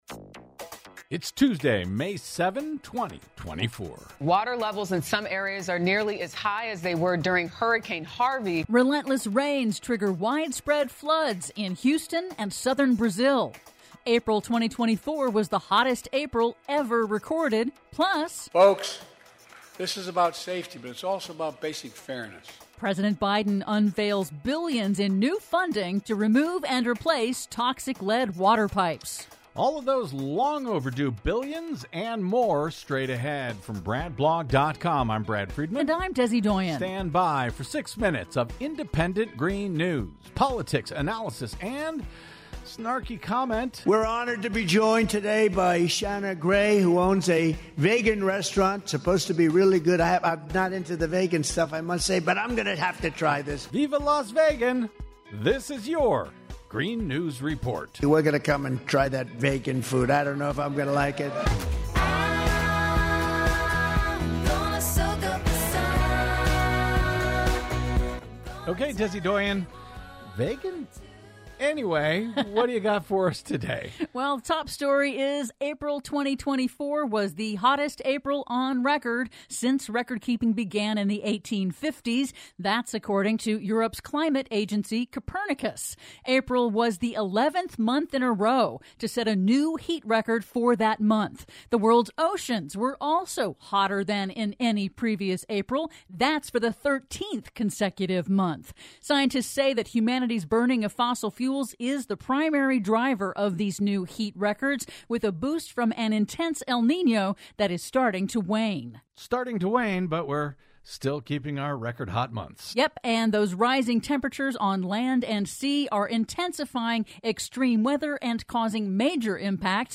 GNR's now celebrating 16 YEARS of independent green news, politics, analysis, snarky comment and connecting climate change dots over your public airwaves!